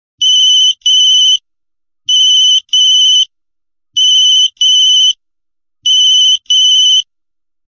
Category: Old Phone Ringtones